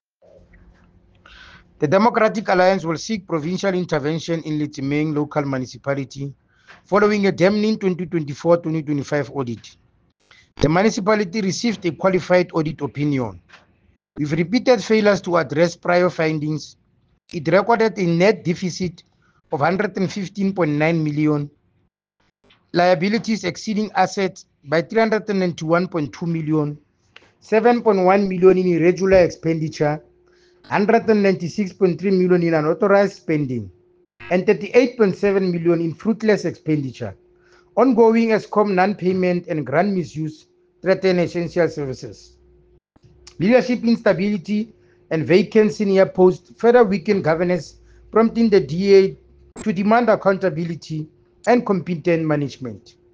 Sesotho soundbites Cllr Thabo Nthapo and